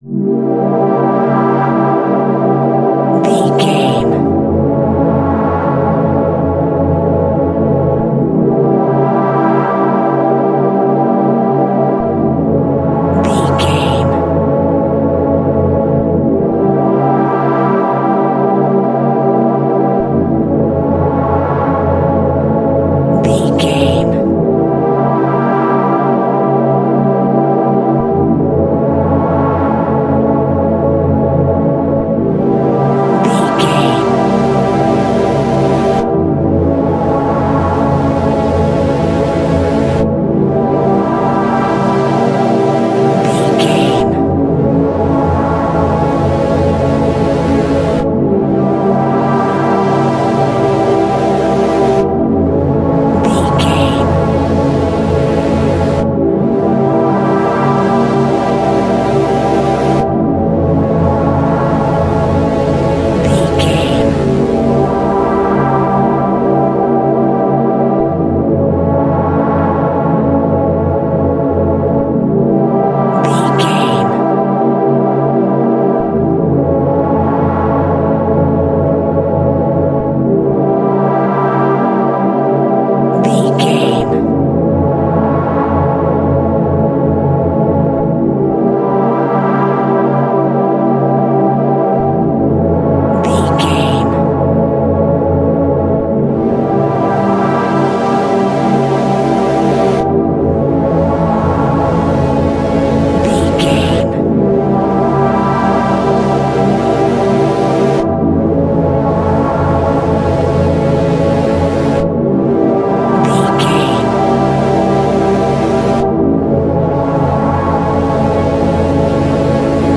Haunting Minor Pads.
Diminished
Slow
ominous
dark
eerie
synthesiser
Horror Ambience
Synth Pads
Synth Ambience